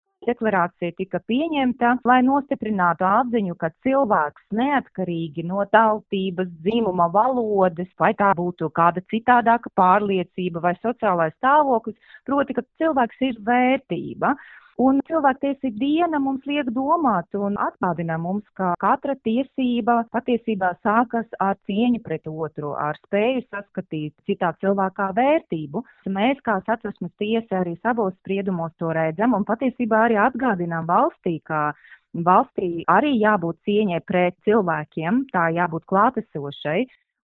Par šīs dienas nozīmīgumu, Skonto mediju grupai pastāstīja Satversmes tiesas priekšsēdētāja Irēna Kucina.
Saruna ar Satversmes tiesas priekšsēdētāju Irēnu Kucinu
Satversmes_tiesas_priekssedetaja_Irena_Kucina.mp3